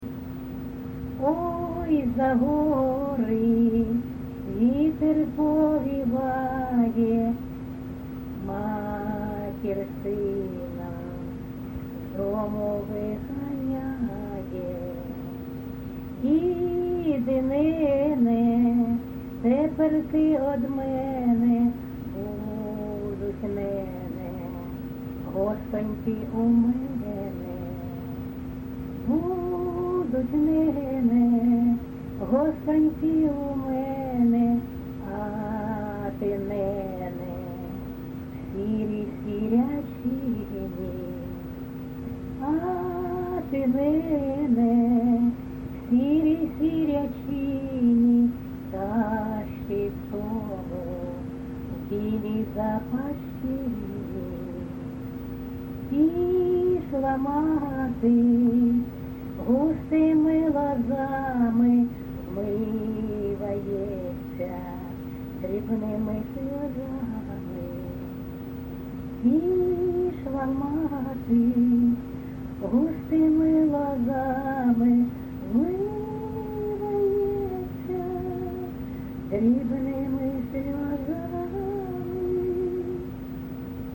ЖанрПісні з особистого та родинного життя
Місце записум. Сіверськ, Артемівський (Бахмутський) район, Донецька обл., Україна, Слобожанщина